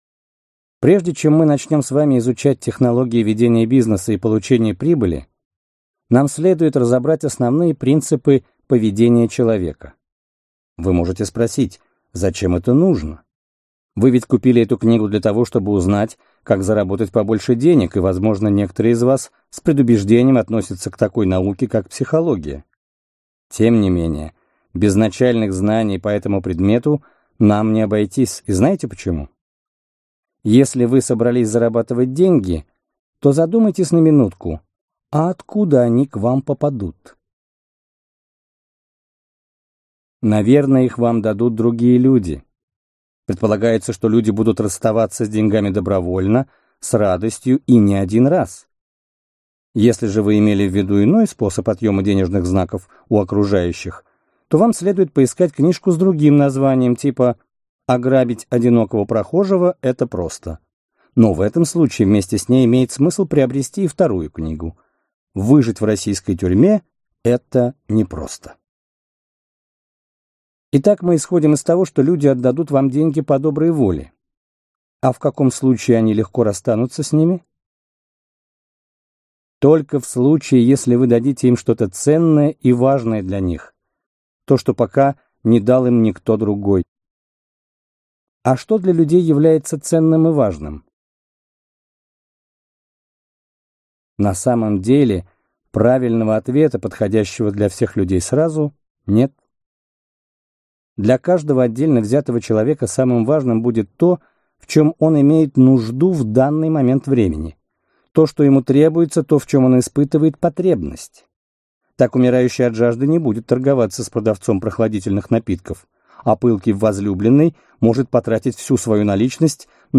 Аудиокнига Успех в бизнесе? Это просто!